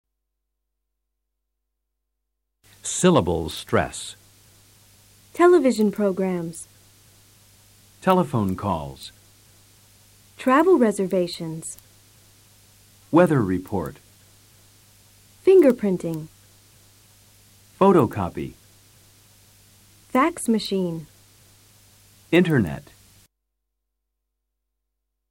Observa la ACENTUACION SILABICA en los siguientes ejemplos.